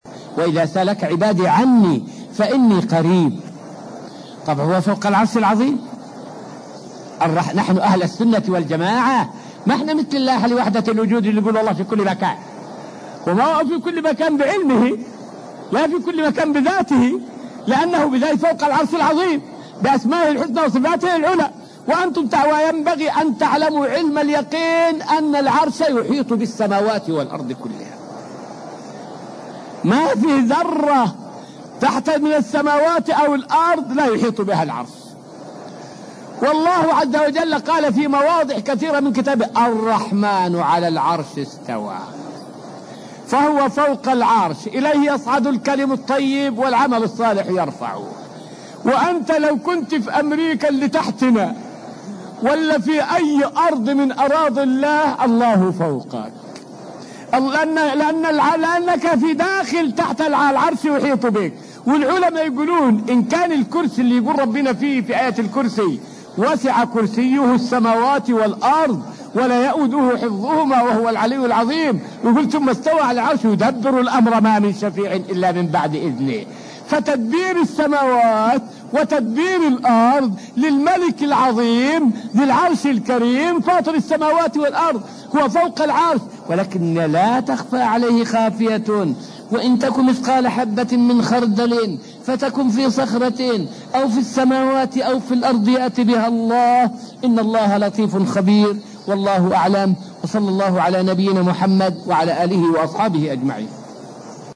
فائدة من الدرس الثالث والعشرون من دروس تفسير سورة البقرة والتي ألقيت في المسجد النبوي الشريف حول معنى لا تخفى على الله خافية.